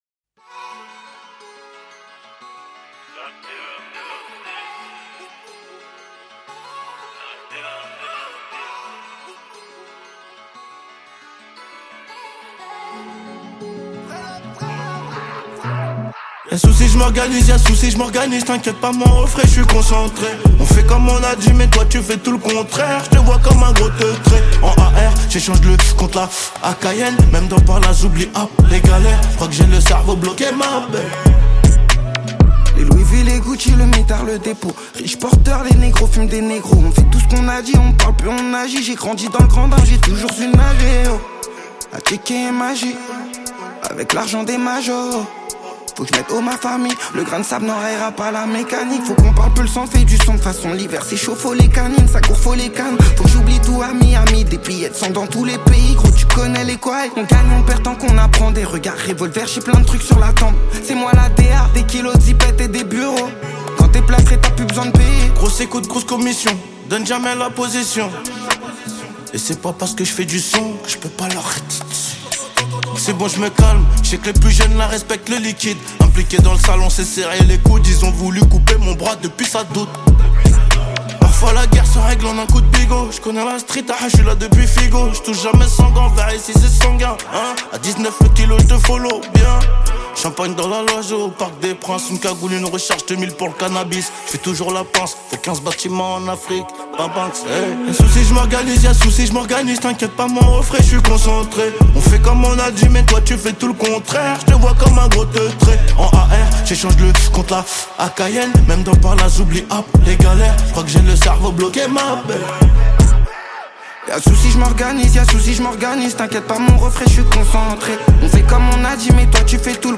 42/100 Genres : french rap, pop urbaine Télécharger